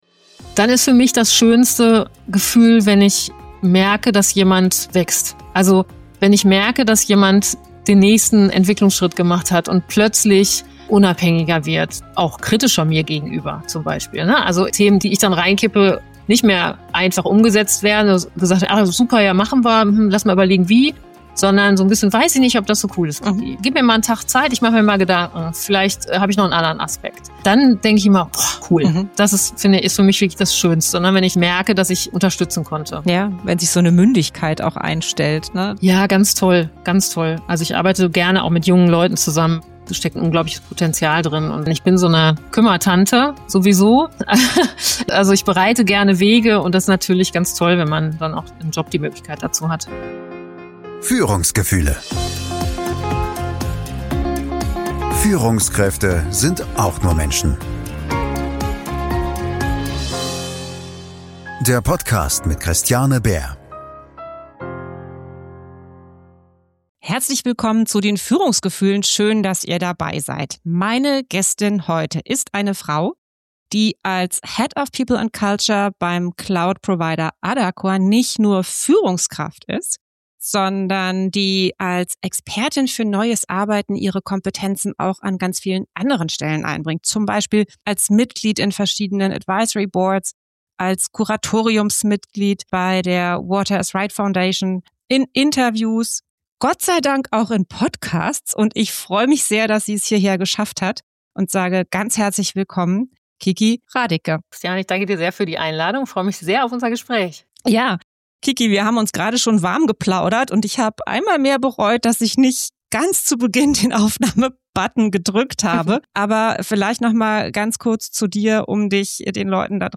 In dieser Folge spreche ich mit einer Frau, die mich nachhaltig beeindruckt hat